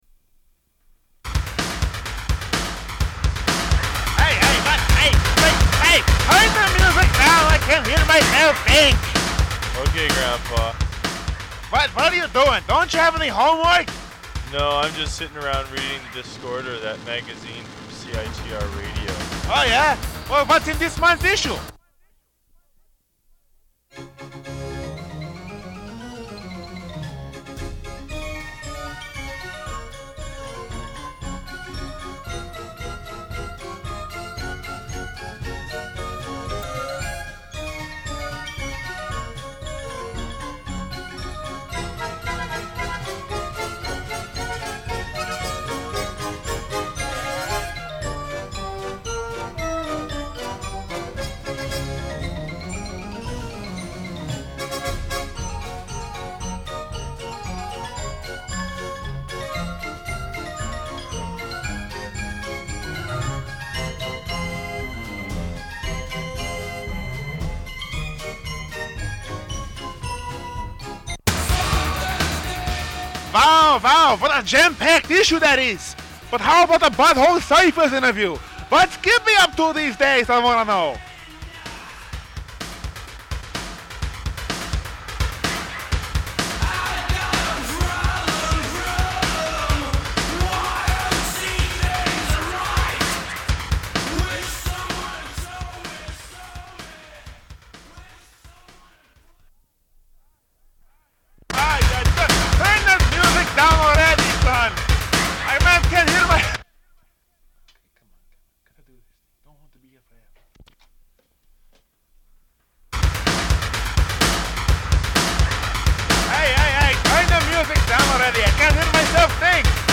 Recording of several outtakes for a promotional spot advertising Discorder magazine.